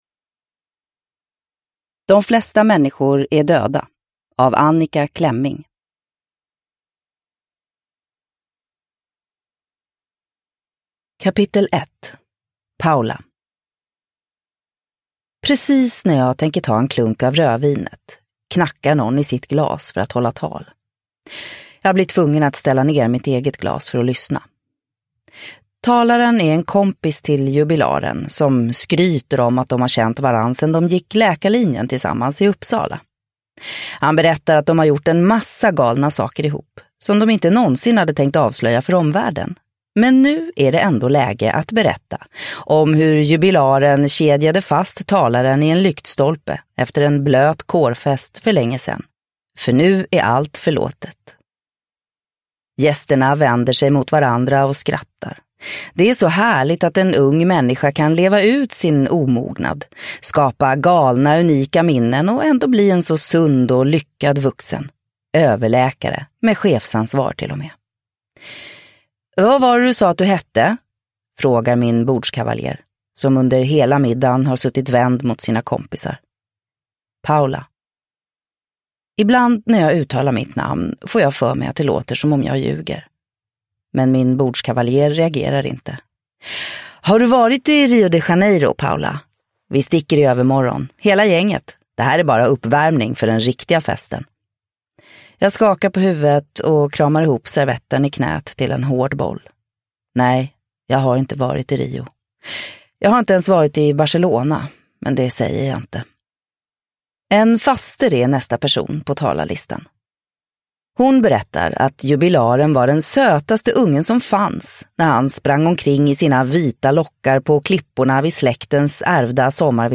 De flesta människor är döda – Ljudbok – Laddas ner